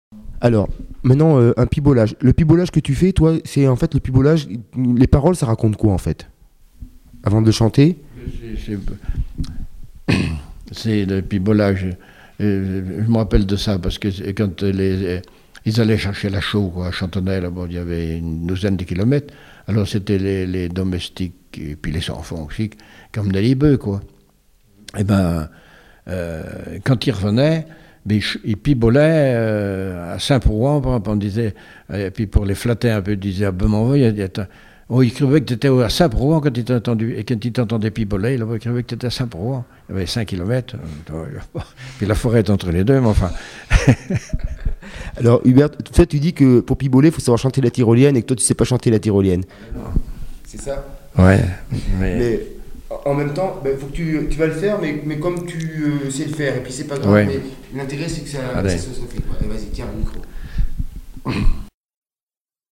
chanteur(s), chant, chanson, chansonnette
Témoignages et chansons